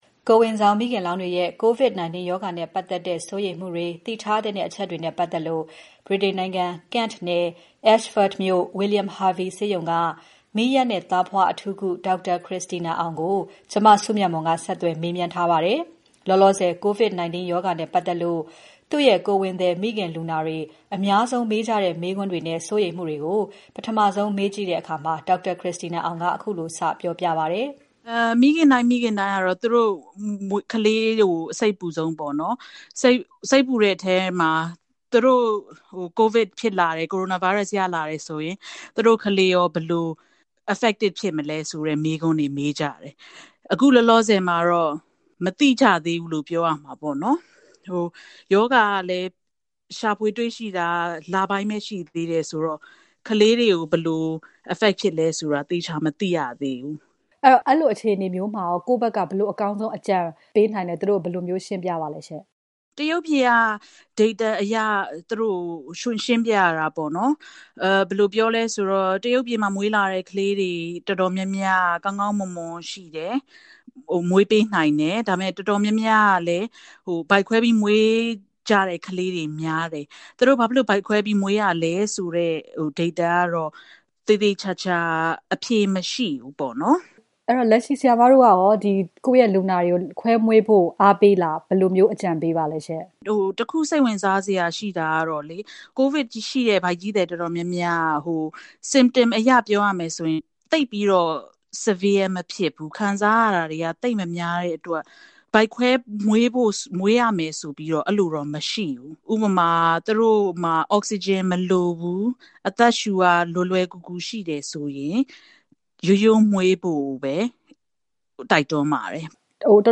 ကိုယ်ဝန်ဆောင်မိခင်လောင်းတွေရဲ့ COVID- 19 ရောဂါနဲ့ပတ်သက်တဲ့ စိုးရိမ်မှုတွေ၊ သိထားသင့်တဲ့ အချက်တွေနဲ့ပတ်သက်လို့ မီးယပ်သားဖွားအထူးကုတယောက်နဲ့မေးမြန်းထားပါတယ်။